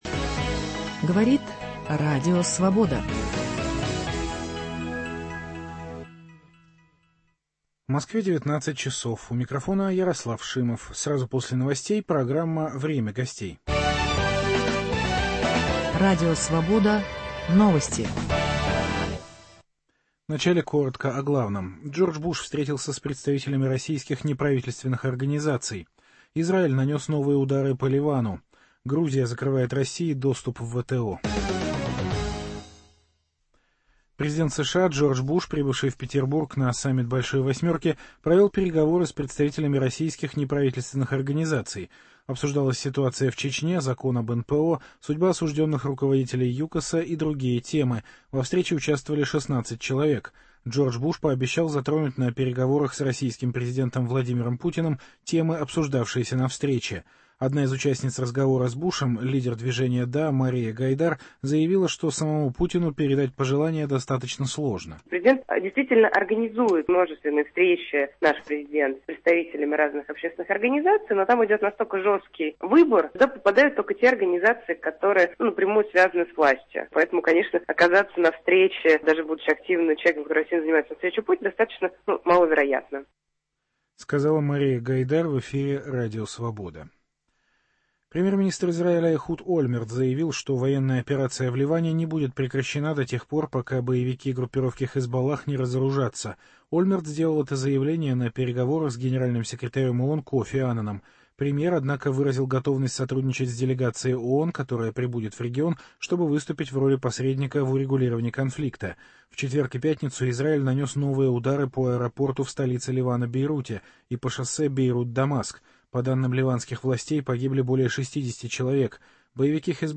беседует с научным руководителем Высшей школы экономики, профессором Евгением Ясиным.